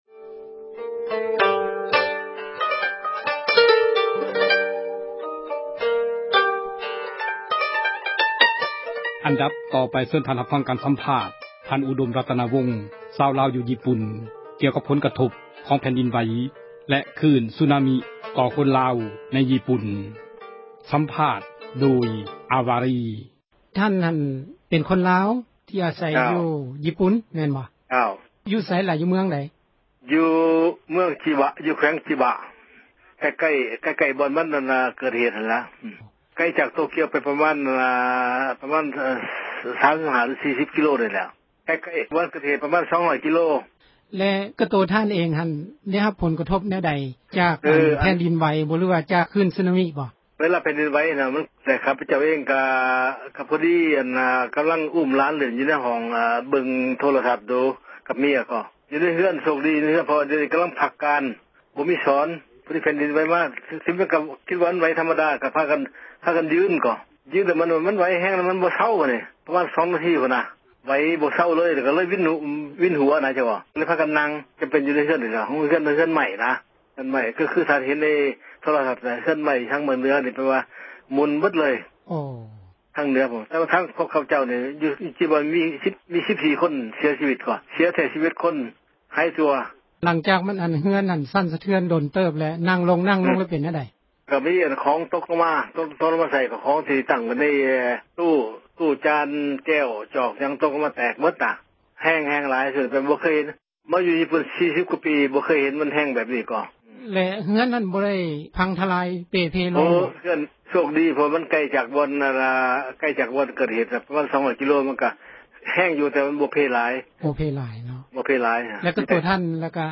ການສໍາພາດ